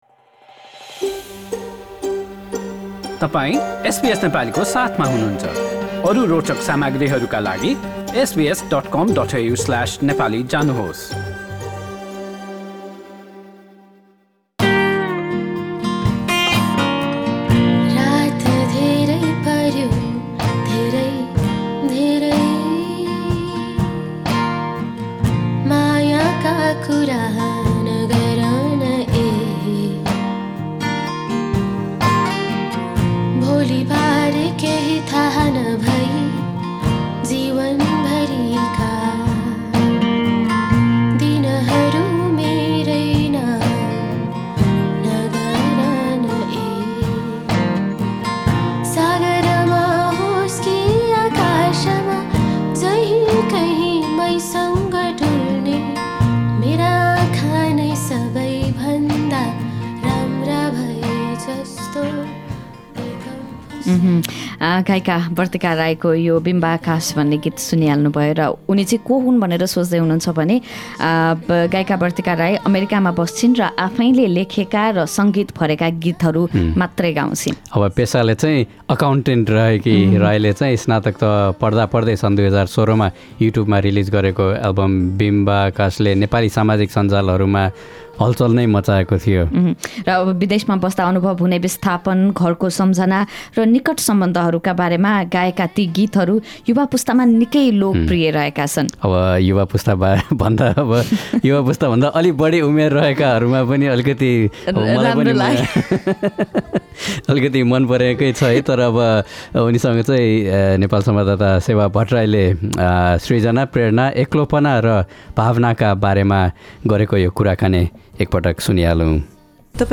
र भावनाका बारेमा कुराकानी गरेकी छिन्।